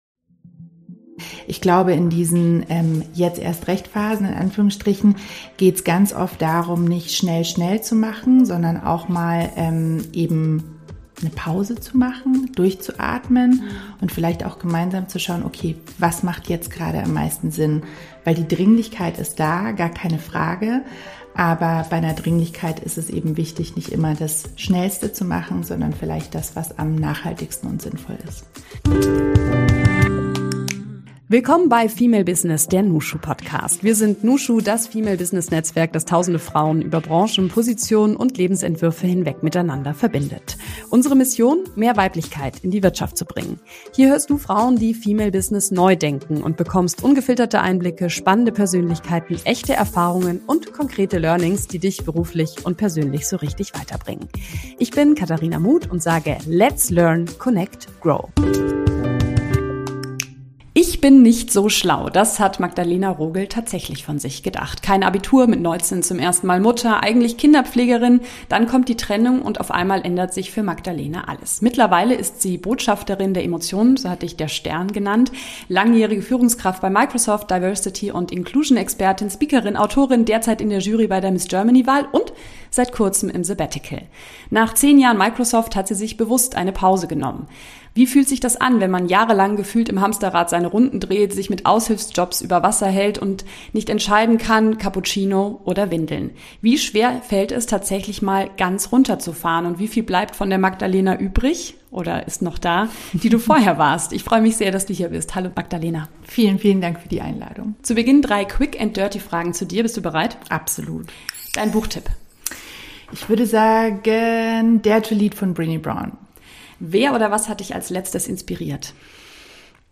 Was macht das mit einer Person, die mit gerade mal 40 Jahren gefühlt schon 3 Leben gelebt hat und durch das Hamsterrad gewuselt ist? Genau darüber haben wir mit ihr gesprochen – bei Cappuccino und Croissants im nushu Headquarter, in einer Atmosphäre, die uns alle gepackt hat.